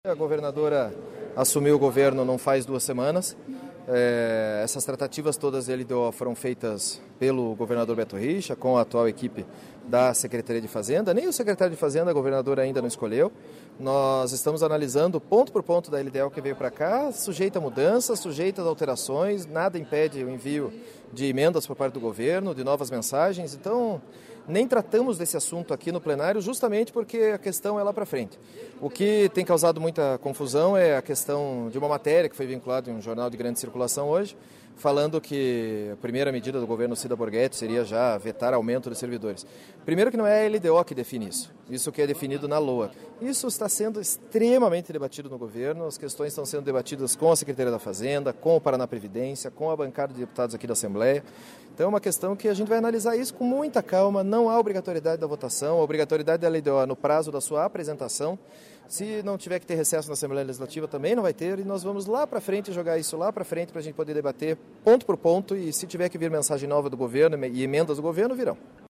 A Lei de Diretrizes Orçamentárias foi tema de entrevista do líder do Governo no Legislativo, deputado Pedro Lupion (DEM), nesta terça-feira (17).